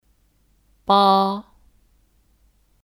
八 (Bā 八)